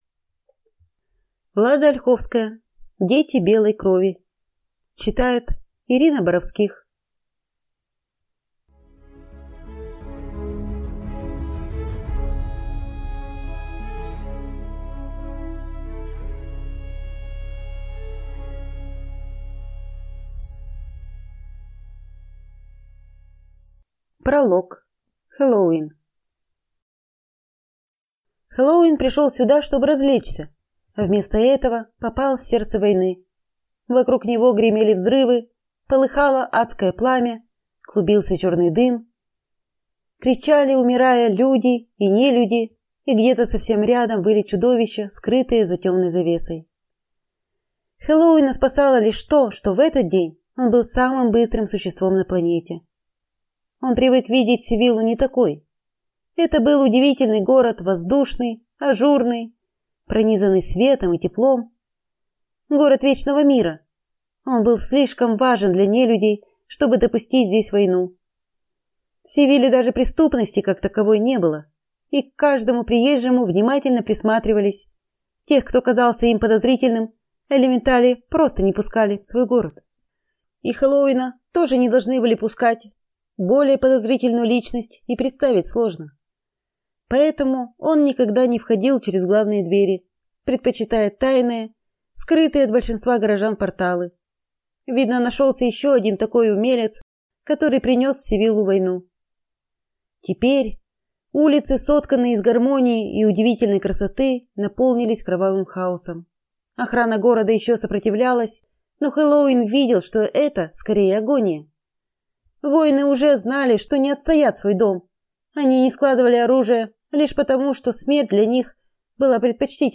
Аудиокнига Дети белой крови | Библиотека аудиокниг